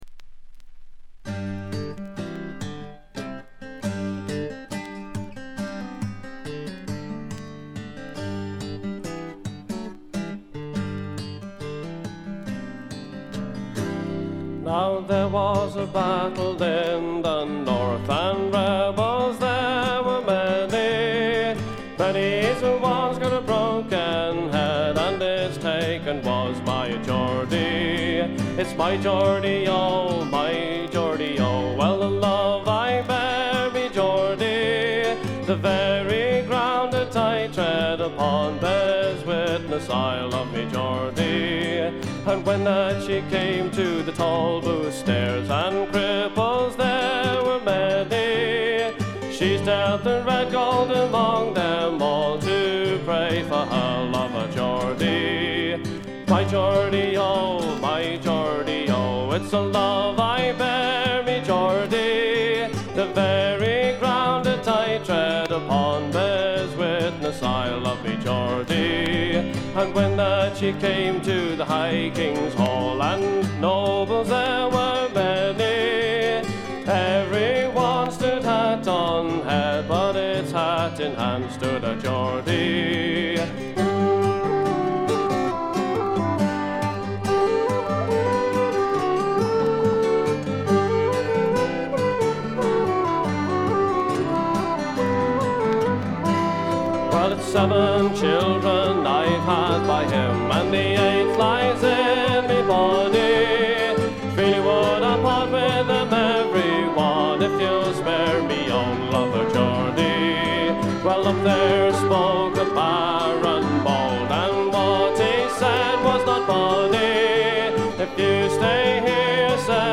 見た目に反してバックグラウンドノイズやチリプチがそこそこ出ます。
本作のもの悲しい笛の音や寒そうな感覚は、おなじみのアイリッシュ・トラッドのような感じです。
アコースティック楽器のみで、純度の高い美しい演奏を聴かせてくれる名作です。
試聴曲は現品からの取り込み音源です。